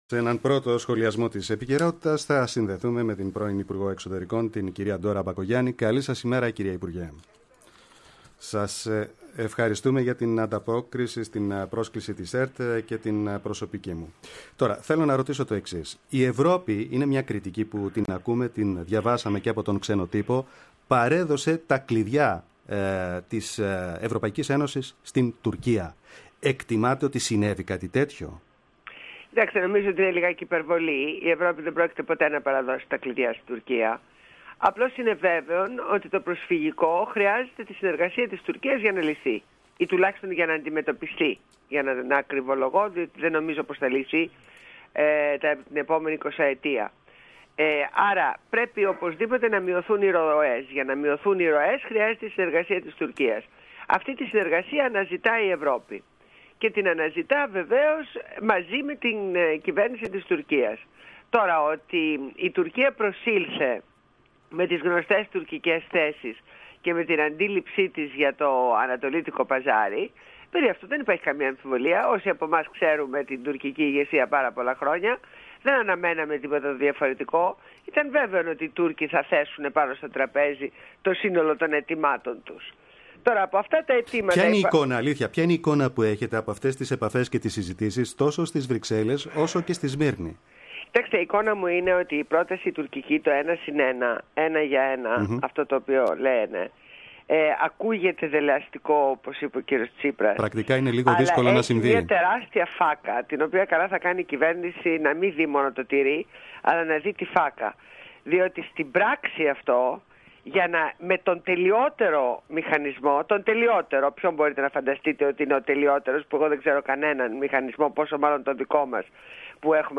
Συνέντευξη στο ραδιόφωνο της ΕΡΤ- Πρώτο πρόγραμμα